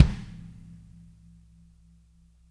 -AMB KIK1C-L.wav